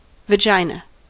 Ääntäminen
US : IPA : [vəˈdʒaɪnə]